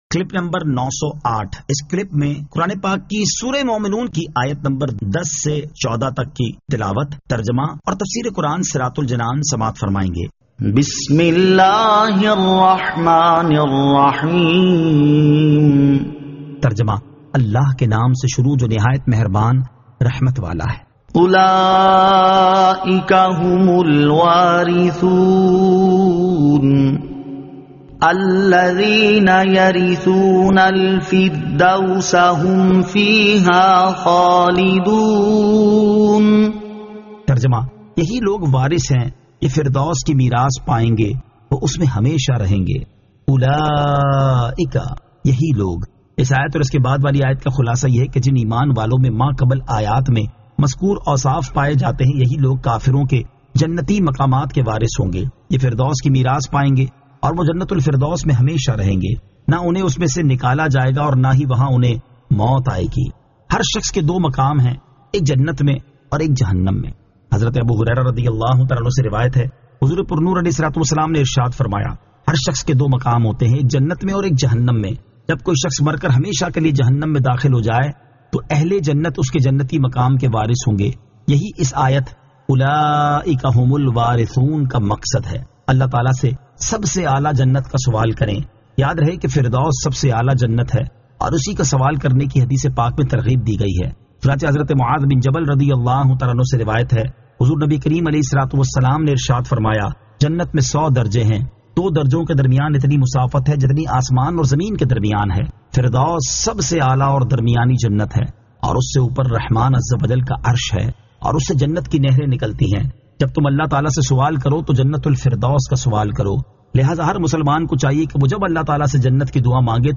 Surah Al-Mu'minun 10 To 14 Tilawat , Tarjama , Tafseer